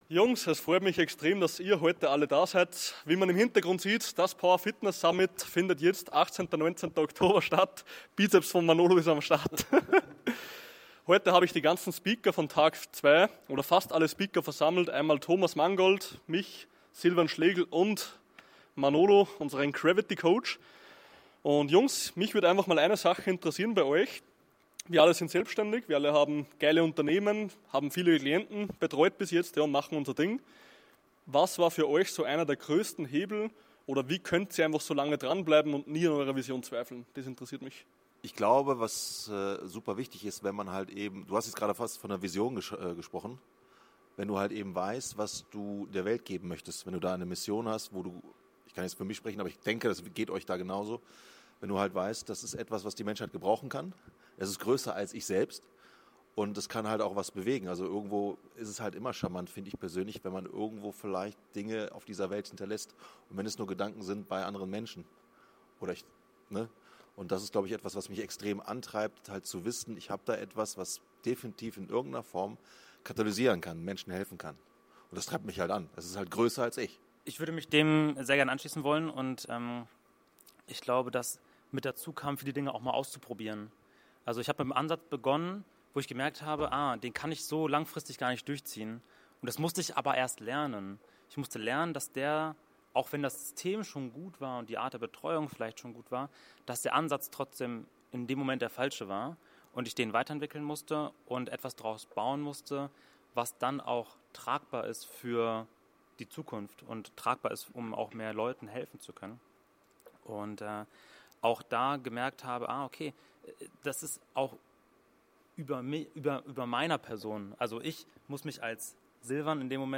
Powerfitness Summit Special Interview